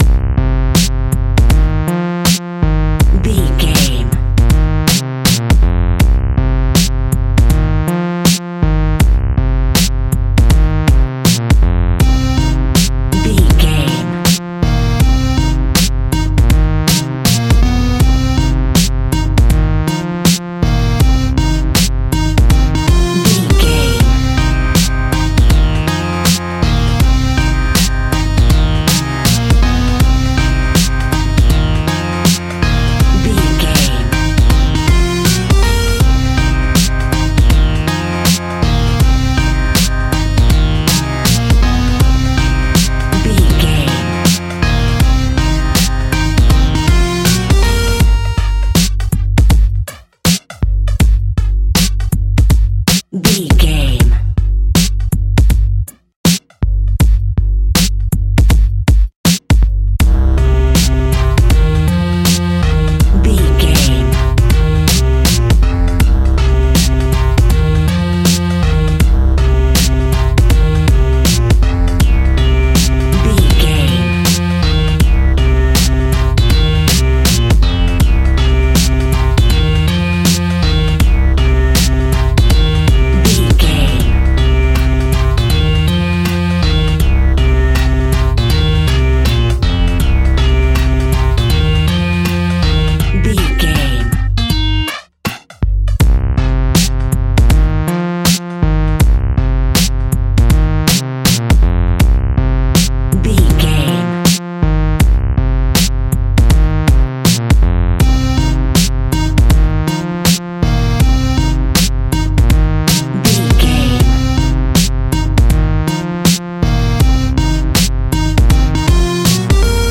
Aeolian/Minor
Slow
hip hop instrumentals
funky
groovy
dirty hip hop
east coast hip hop
electronic drums
synth lead
synth bass